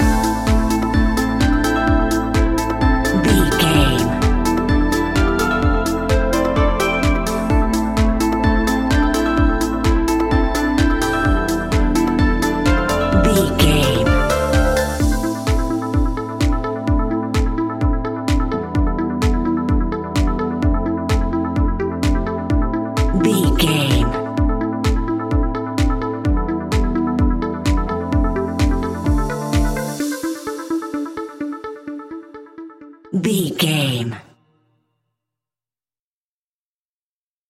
Aeolian/Minor
dark
futuristic
groovy
synthesiser
drum machine
house
electro
electro house
synth leads
synth bass